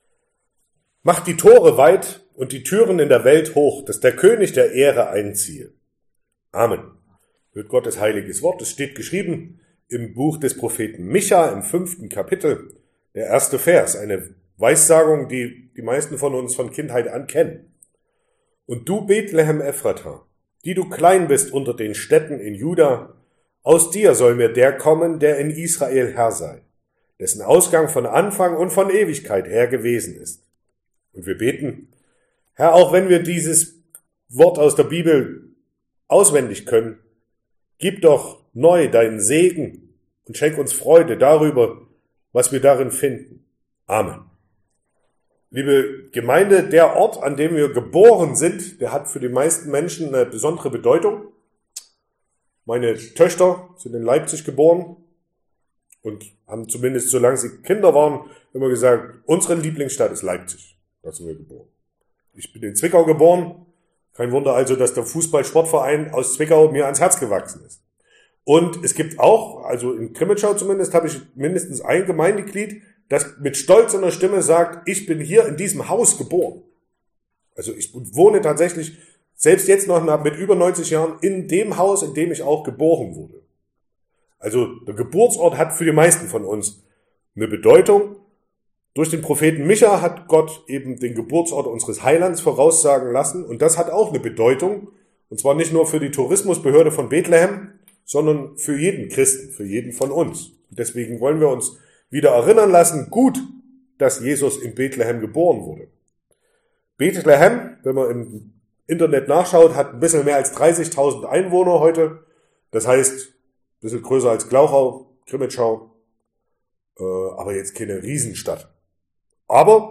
Kirchweihgottesdienst/ 4. Advent 2022